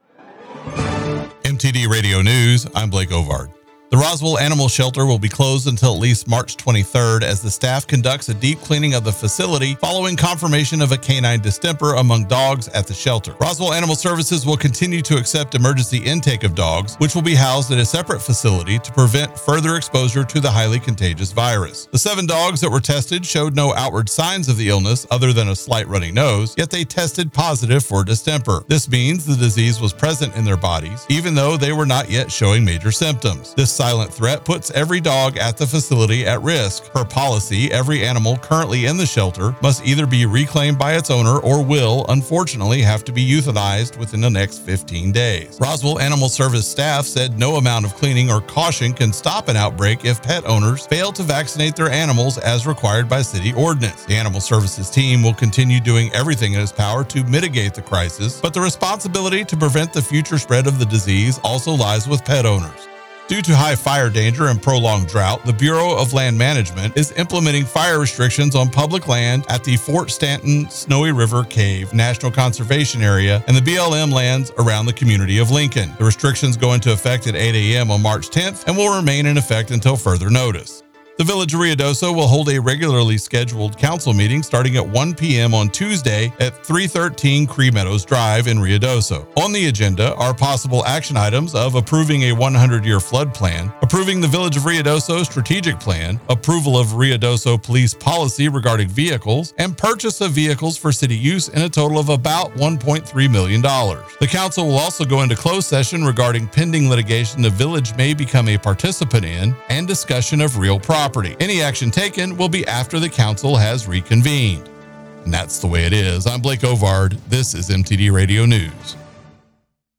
Mix 96.7 News – Ruidoso and New Mexico